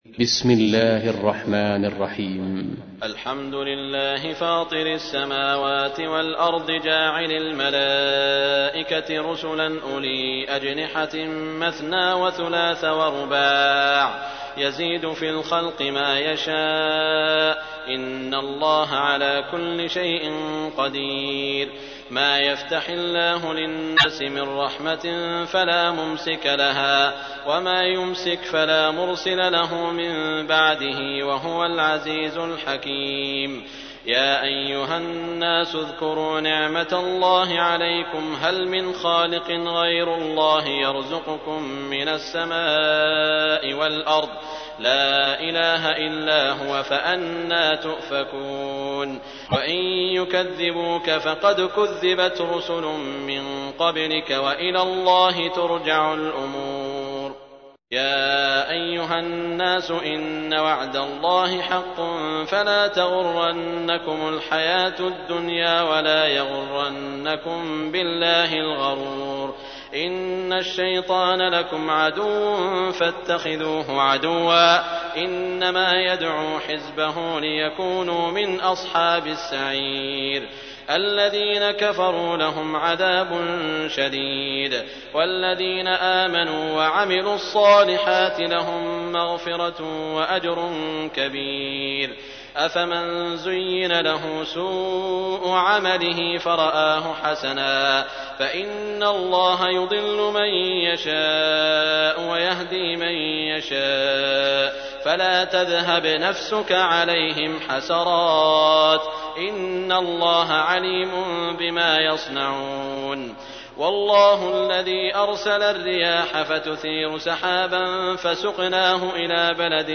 تحميل : 35. سورة فاطر / القارئ سعود الشريم / القرآن الكريم / موقع يا حسين